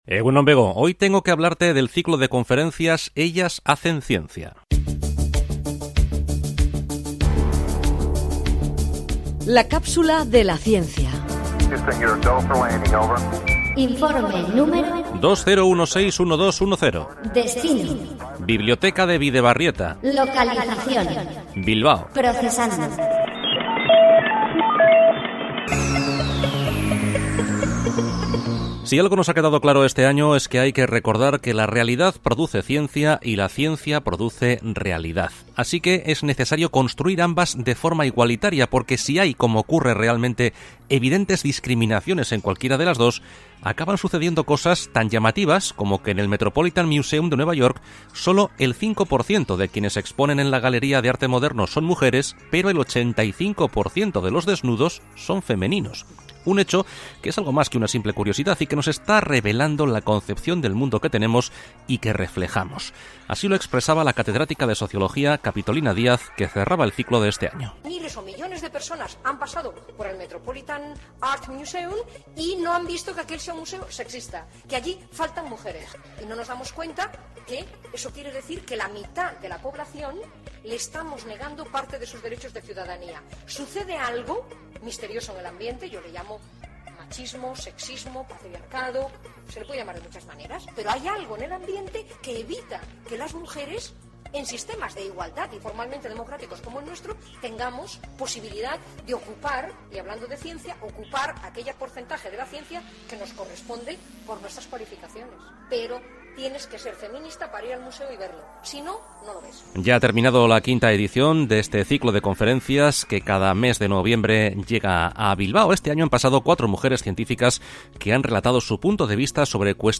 Audio: Ciclo de conferencias "mujeres y ciencia" en la Biblioteca de Bidebarrieta